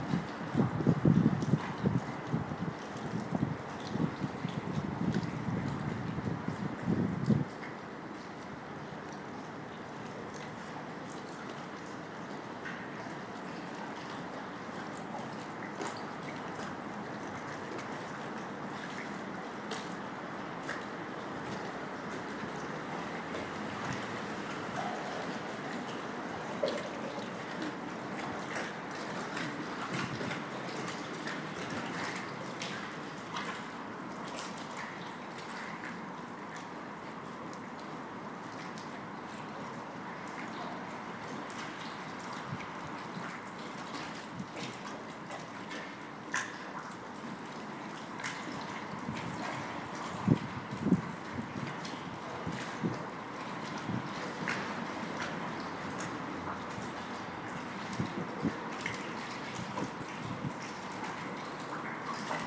Canal flowing under a bridge
Water flowing through a canal in central Birmingham. 16.04.13